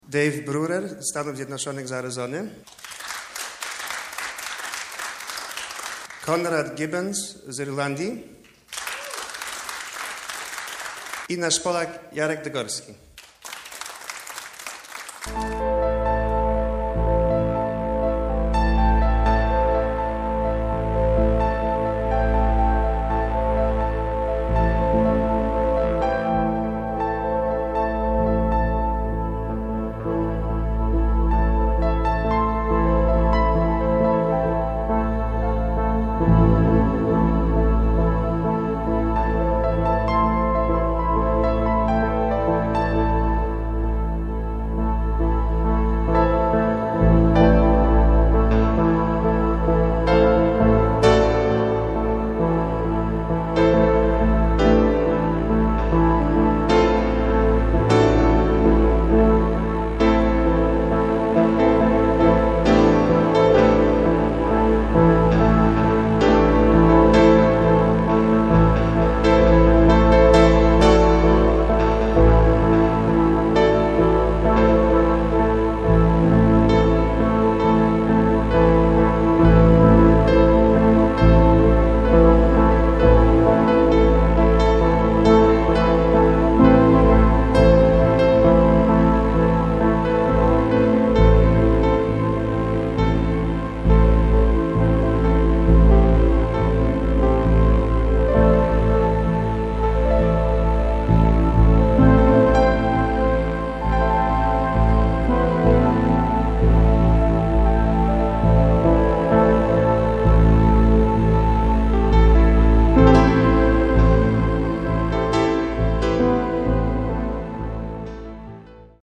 w sali teatru j.w. wystąpili